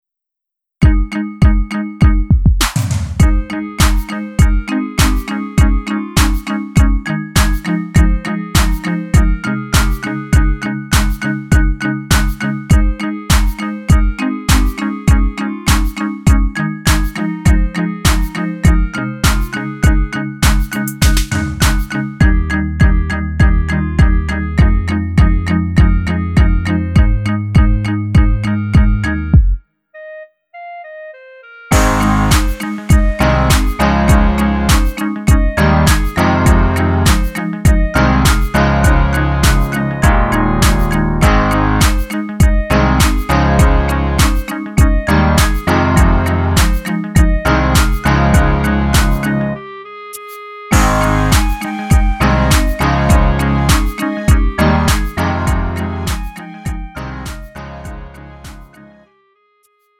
음정 -1키 3:07
장르 가요 구분